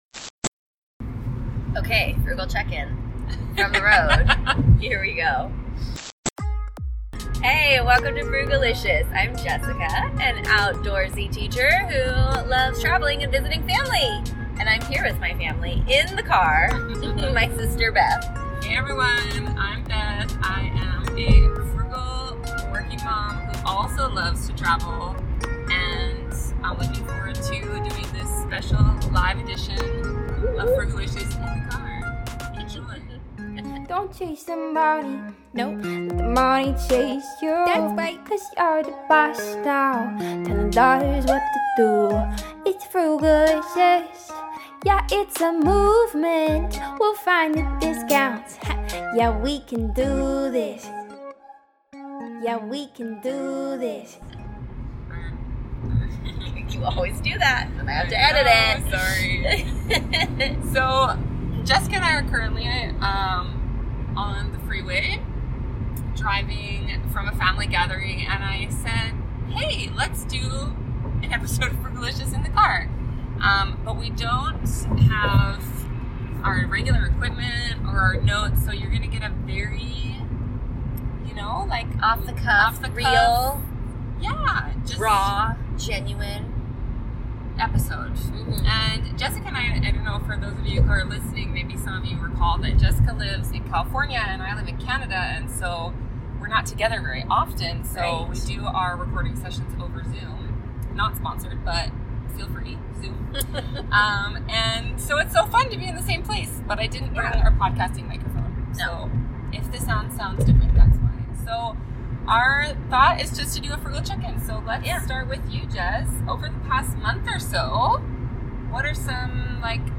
#37 – Let’s Talk in the Car